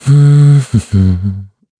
Siegfried-Vox_Hum_kr.wav